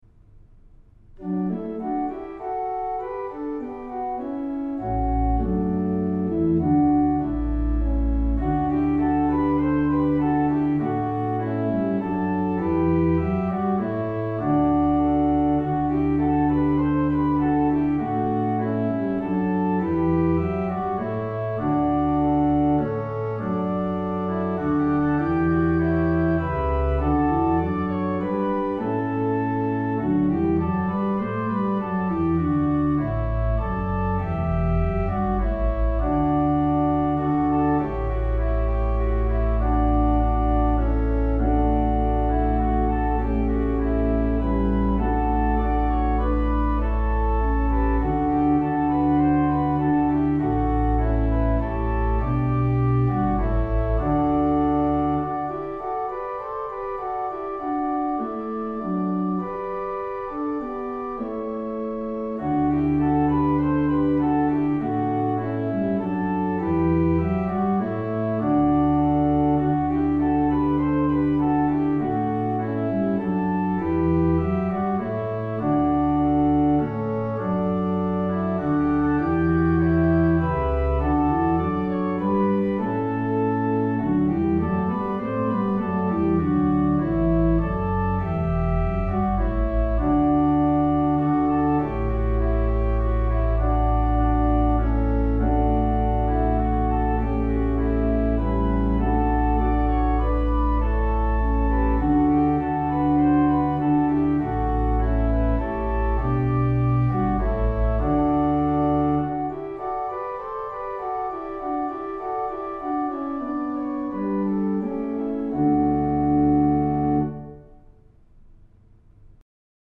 Voicing: SA Men, upper/lower, accompanied or unaccompanied
Backing Track